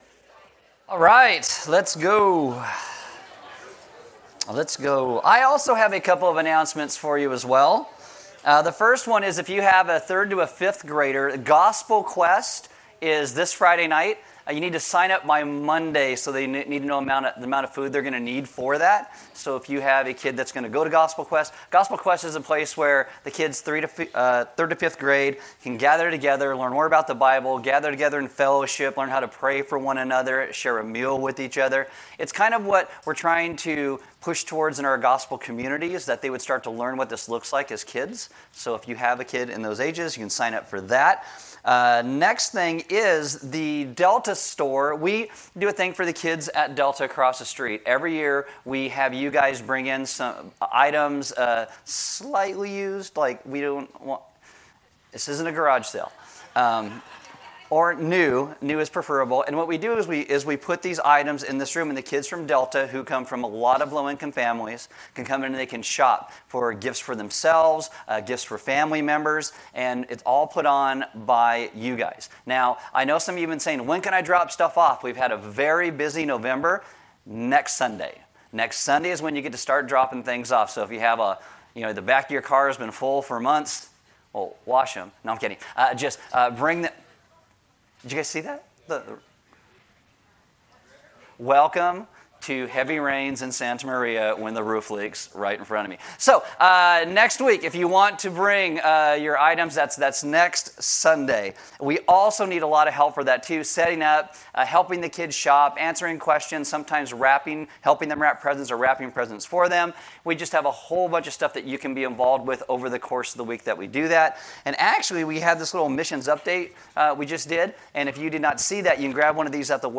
Service Audio While divorce is painful and not God's original design, it is not an unforgivable sin.